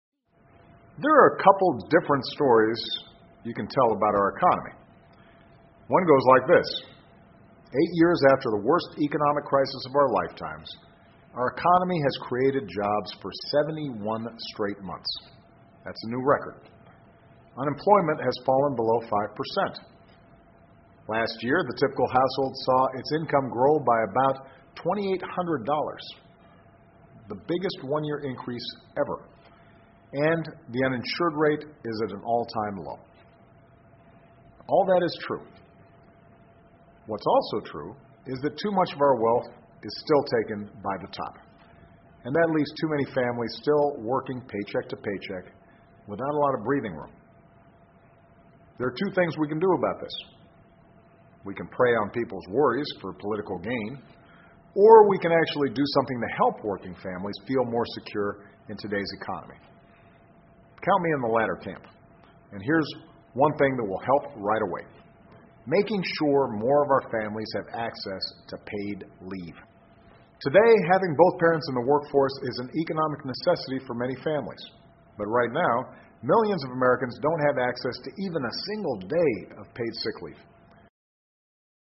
奥巴马每周电视讲话：总统呼吁给予工薪家庭带薪病假的福利（01） 听力文件下载—在线英语听力室